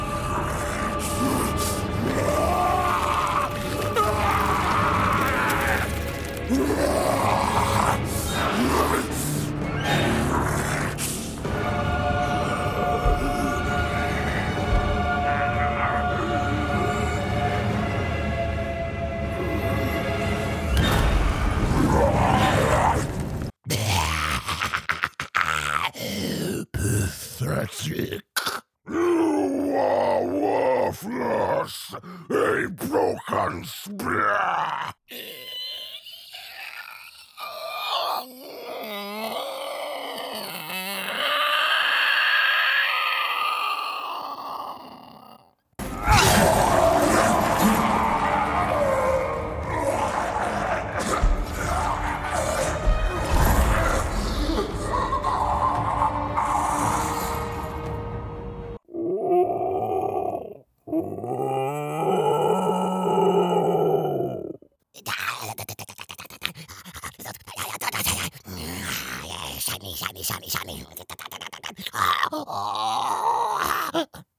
Creature/Non-Human Showreel
Male
Birmingham
Gravitas
Gravelly